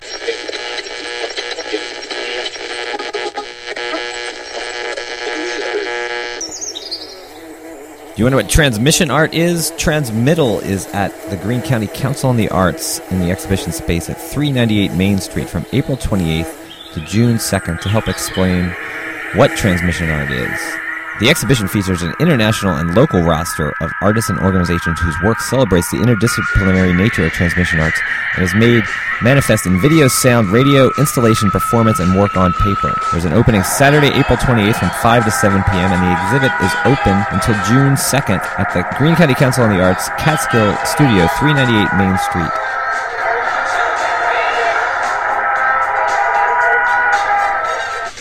An Official WGXC PSA for Transmittal art show at Greene County Council of Arts in Catskill Apr. 28-June 2. (Audio)